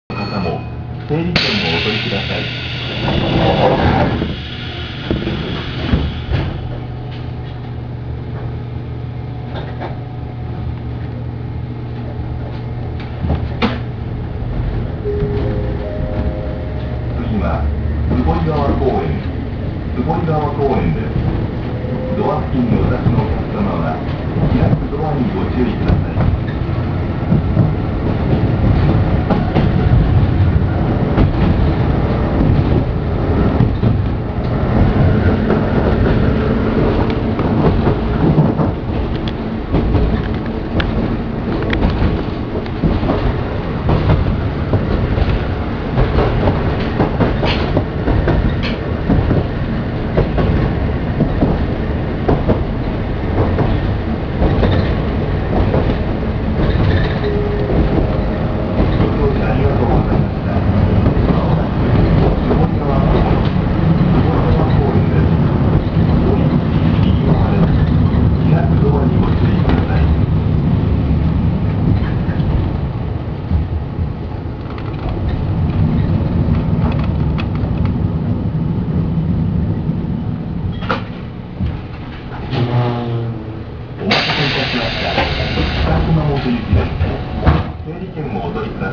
・5000系走行音
【菊池線】打越〜坪井川公園（1分29秒：484KB）…5101Aにて
あまり速度を出す区間が無い（速度を出せないのかもしれないけど…）ので目立った音ではありませんが、一応カルダン駆動なので音自体は地味です。
ワンマン運転なので自動放送が付いていますが、地方私鉄の自動放送としては珍しく男声放送。アナウンサーのような声です（後に別の放送に交換されています）。